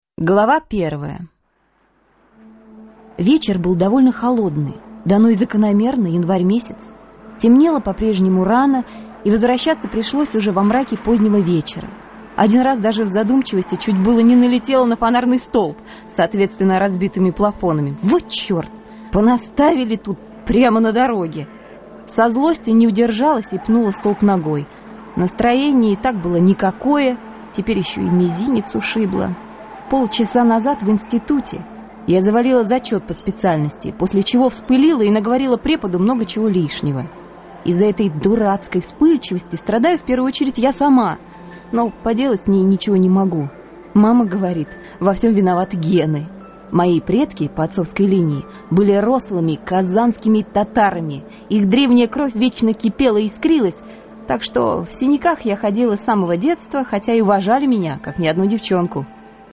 Аудиокнига Профессиональный оборотень | Библиотека аудиокниг